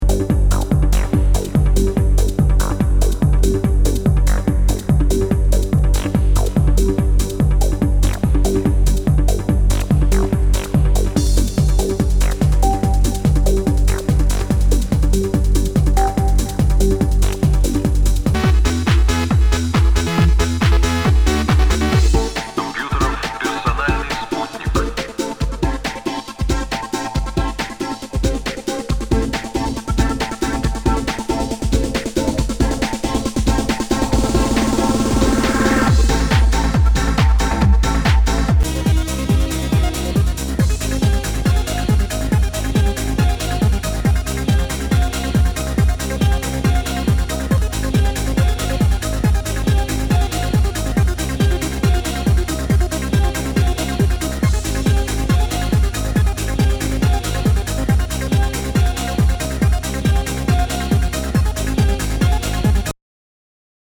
HOUSE/TECHNO/ELECTRO
ナイス！トランス！